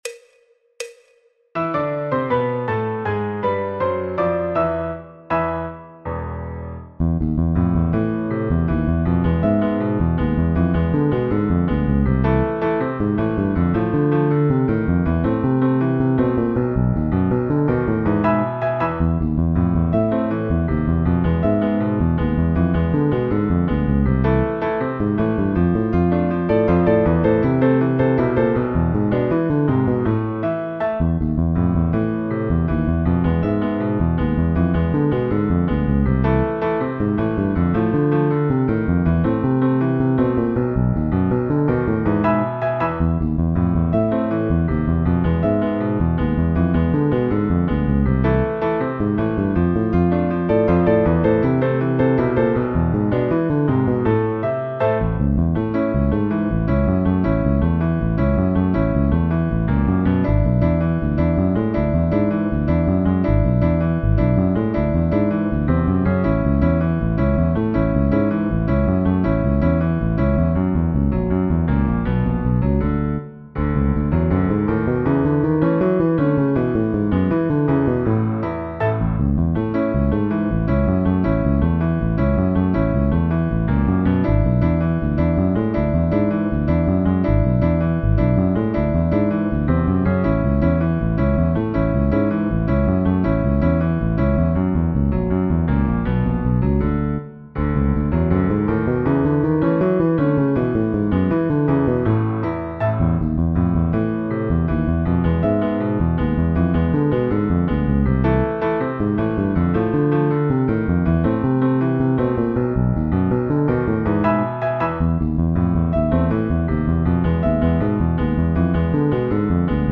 Punteo en La menor y La Mayor
Choro, Jazz, Popular/Tradicional